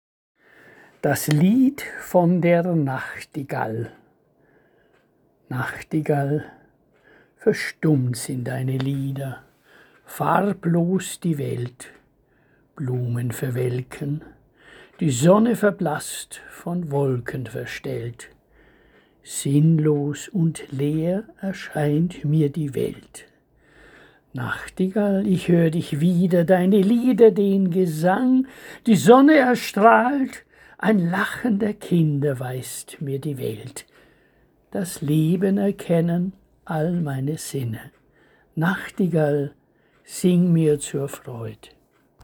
Lesung eigener Gedichte